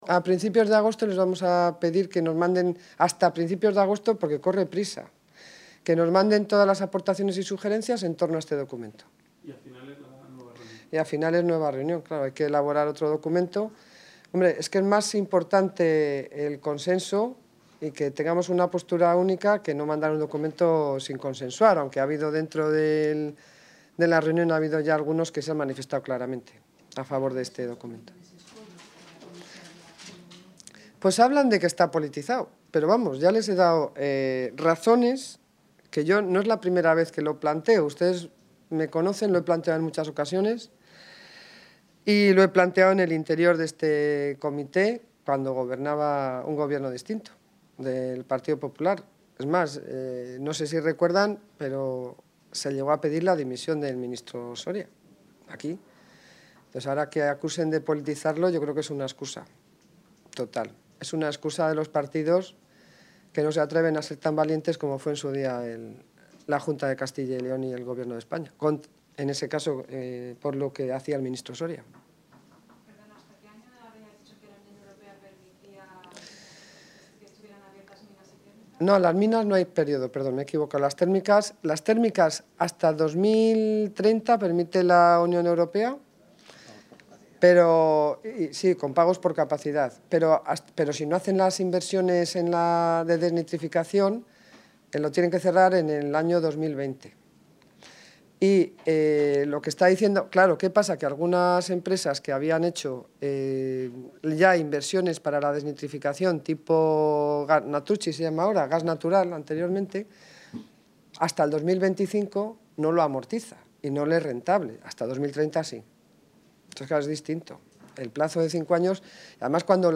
Declaraciones de la consejera de Economía y Hacienda.